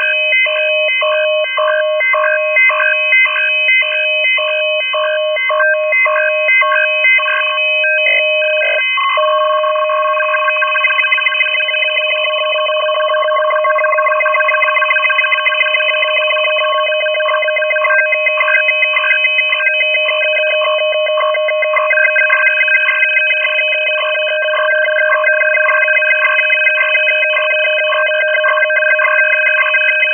3x 1440 Hz shift VFT
UKR_VFT_WIDE_IDLE_TFC.WAV